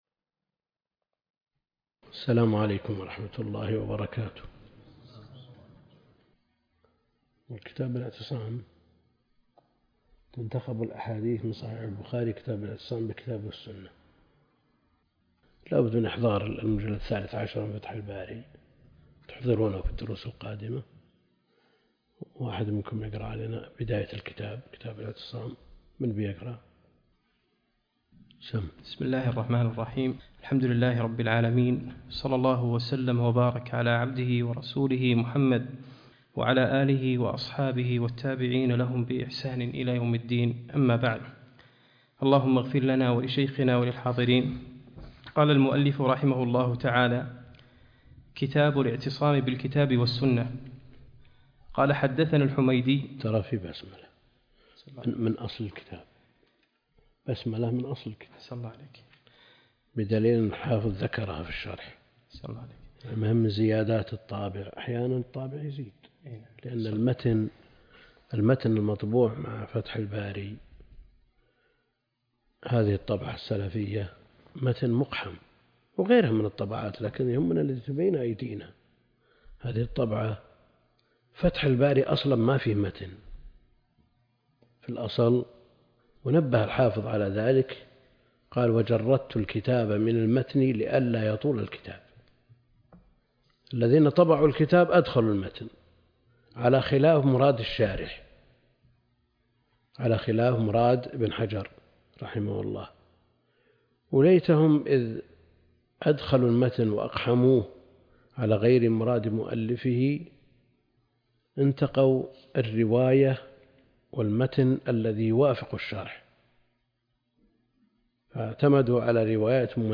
الدرس (1) التحليلي - الدكتور عبد الكريم الخضير